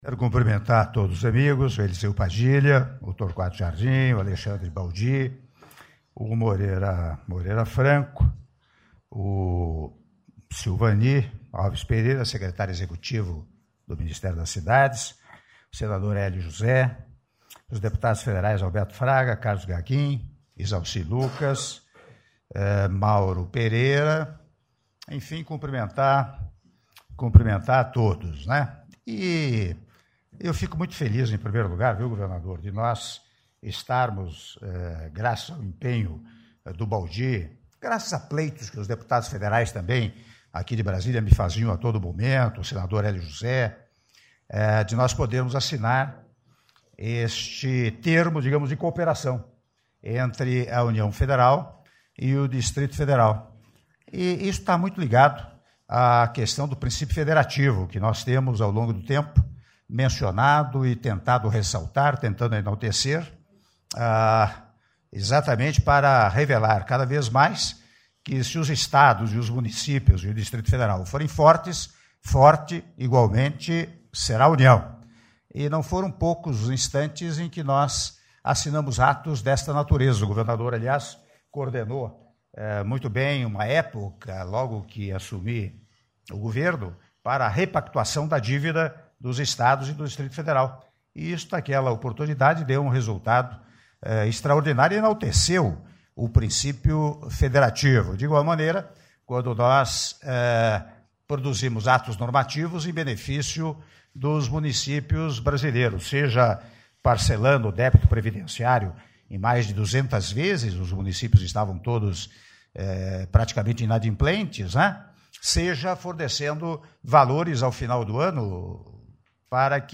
Áudio do discurso do Presidente da República, Michel Temer, durante anúncio de recursos para expansão e modernização do Metrô do DF - Palácio do Planalto - (04min39s)